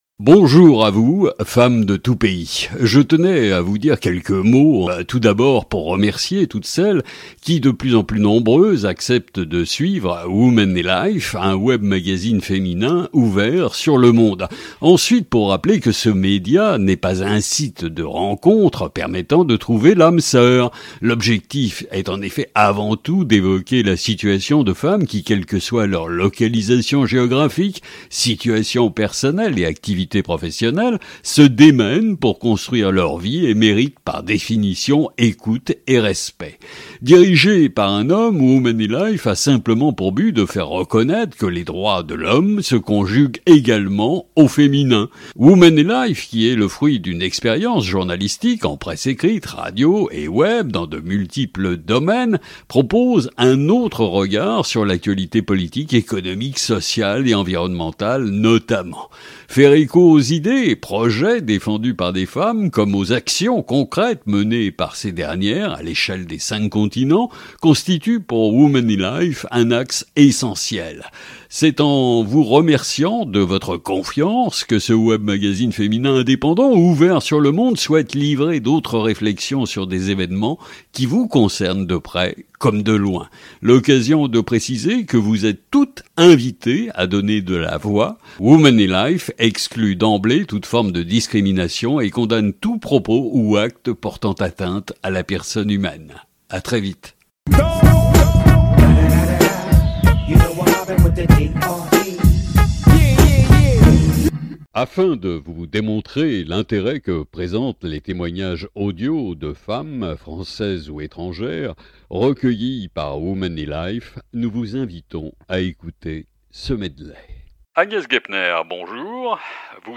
Car les extraits d’interviews et déclarations qui se trouvent arbitrairement repris dans ce document sonore, n’ont en réalité rien perdu de leur actualité.